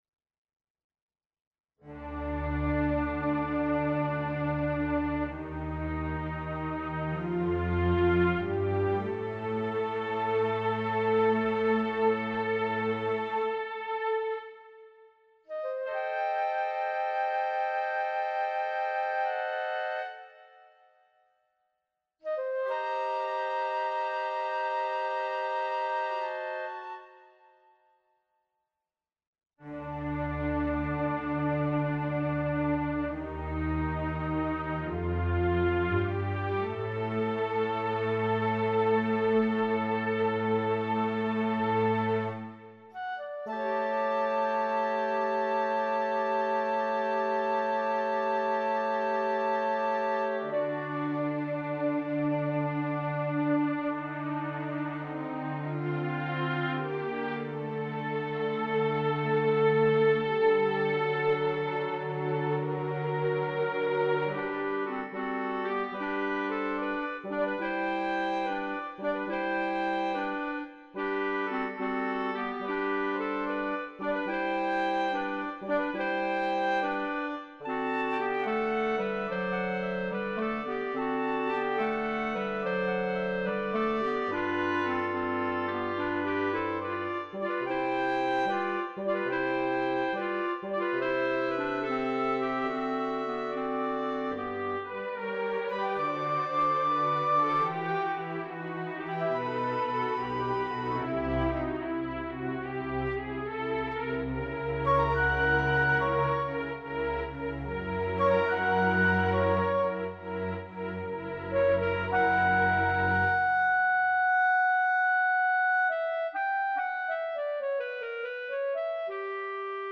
This is a full score realization.
Overture